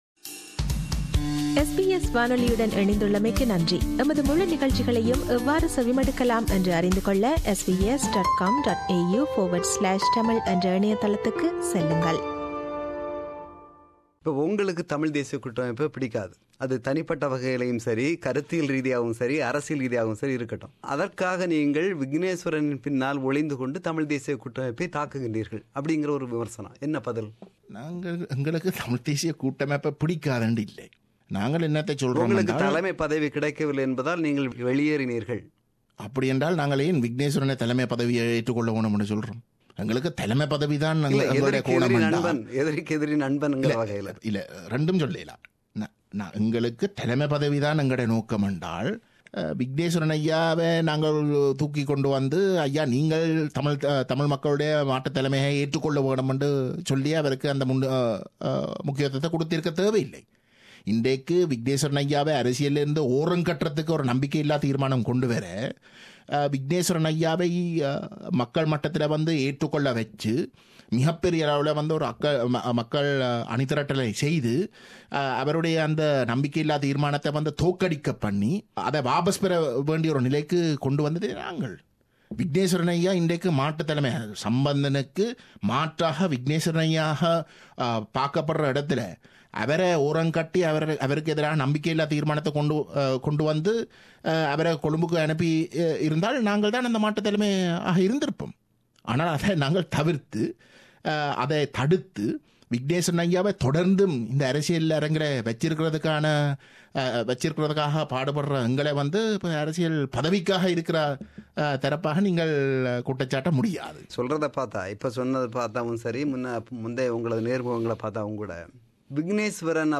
Interview with Gajendrakumar Ponnambalam – Part 2